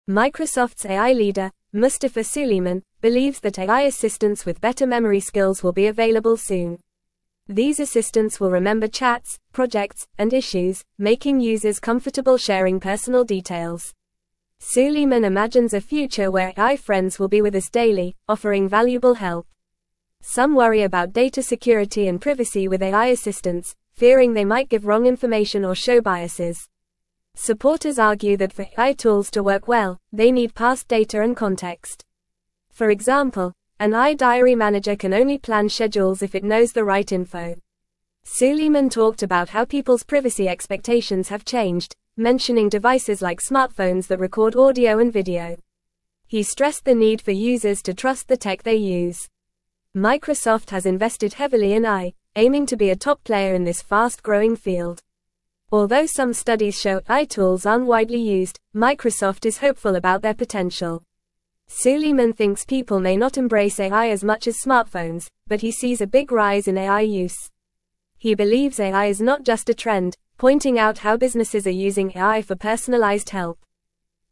Fast
English-Newsroom-Upper-Intermediate-FAST-Reading-Microsofts-Head-of-AI-Predicts-Advanced-Memory-Assistants.mp3